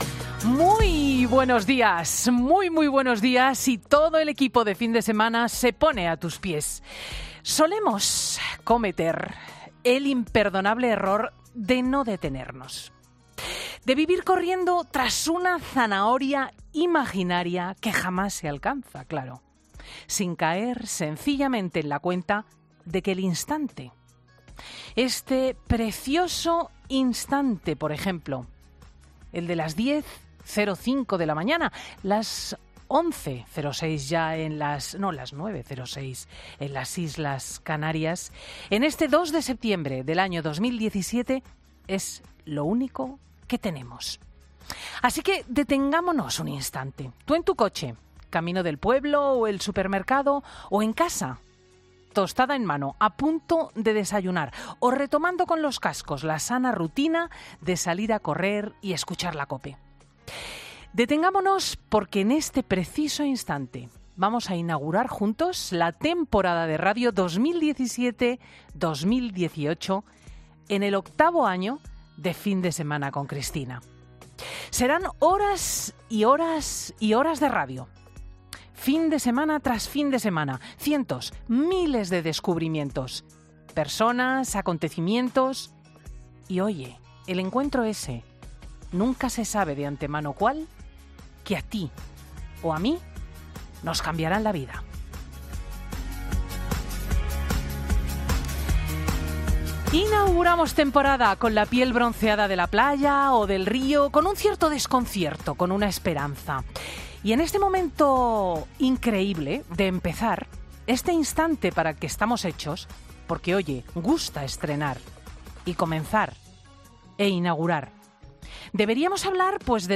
La alerta de atentado en La Rambla que recibieron los Mossos de la CIA y que estos desmienten, en el editorial de Cristina López Schlichting en 'Fin de Semana'.